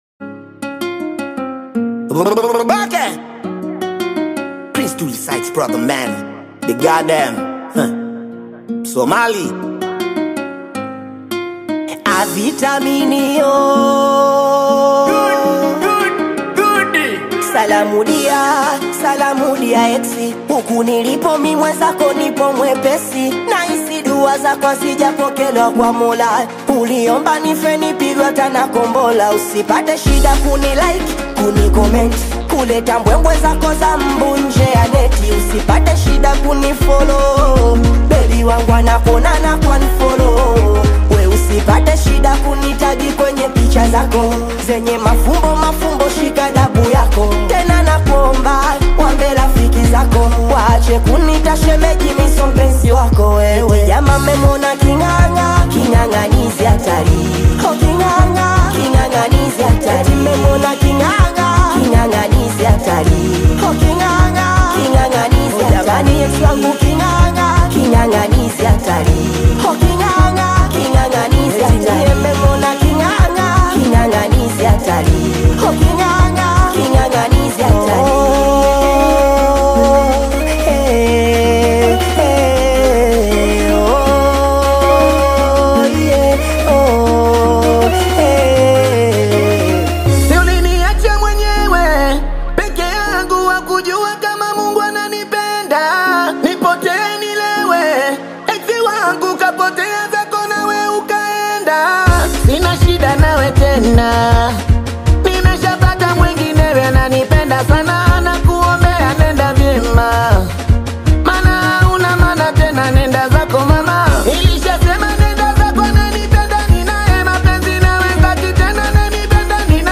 high‑energy Singeli/Bongo Flava anthem